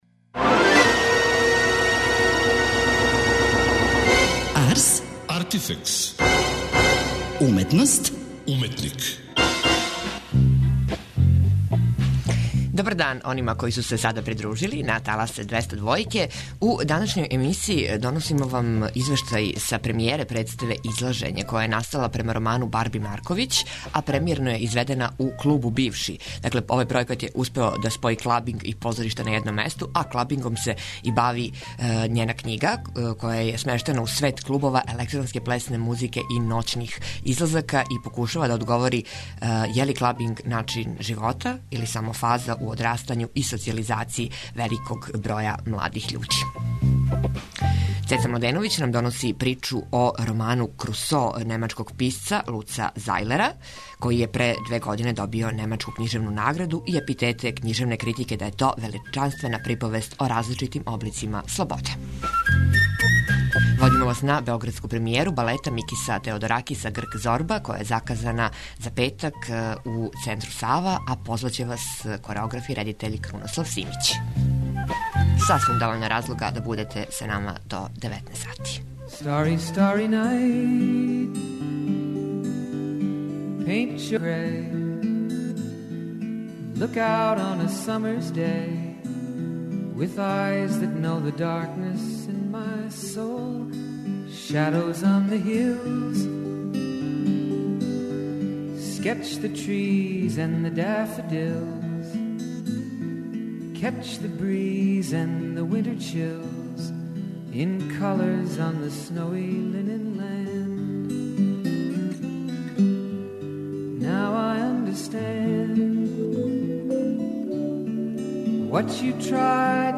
разговору